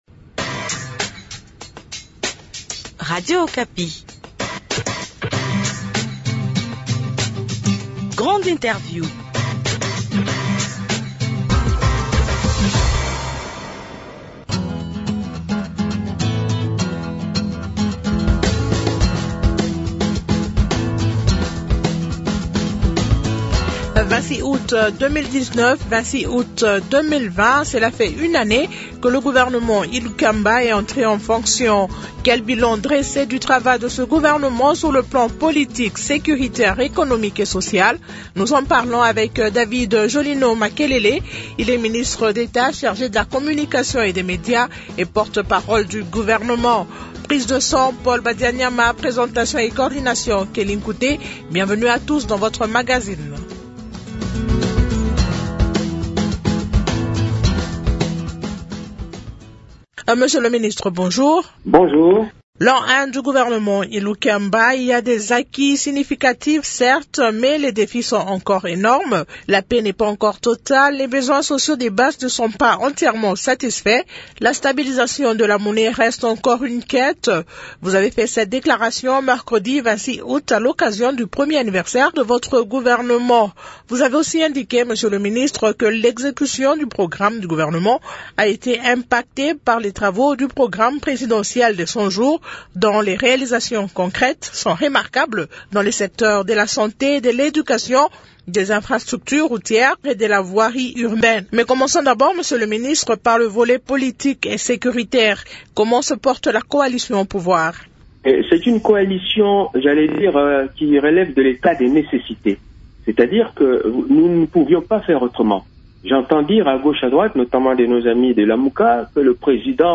Propos de David Jolino Makelele, ministre d'Etat de la communication et médias et porte-parole du gouvernement ,invité du magazine Grande Interview